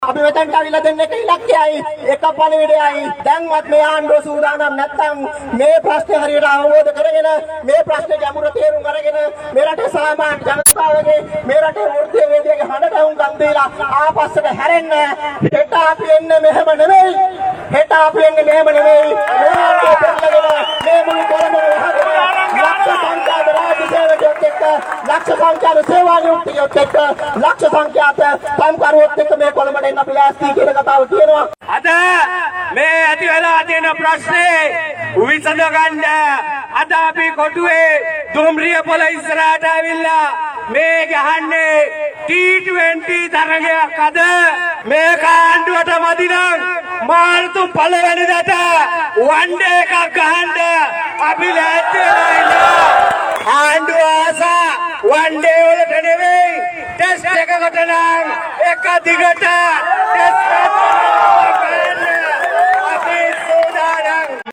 මේ අතර නව ආදායම් බදු සංශෝධනයට එරෙහිව වෘත්තීයවේදීන්ගේ වෘත්තීය සමිති එකමුතුව විසින් අද දින සංවිධානය කරන ලද විරෝධතාවයෙන් අනතුරුව ප්‍රධාන විරෝධතාව කොළඹ කොටුව දුම්රිය ස්ථානය ඉදිරිපිටදි පැවැත් වුණා.
මේ එම විරෝධතාවයේදී වෘත්තීය සමිති සාමාජිකයින් පළ කළ අදහස්.